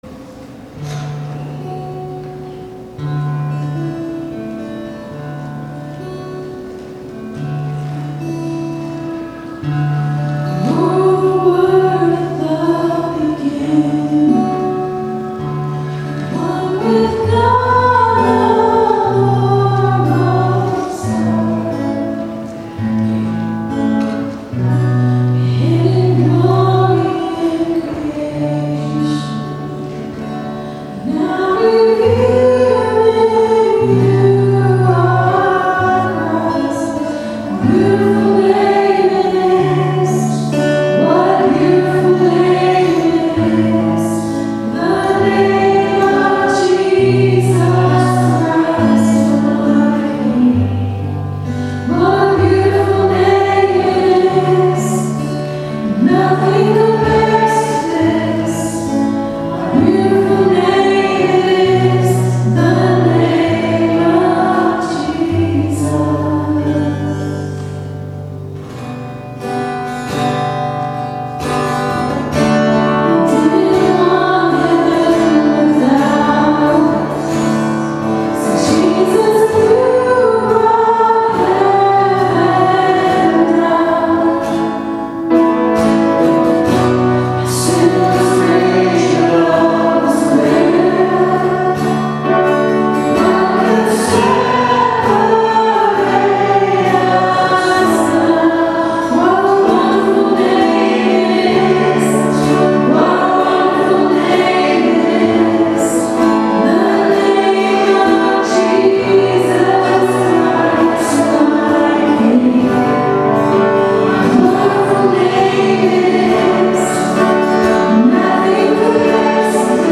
guitar
piano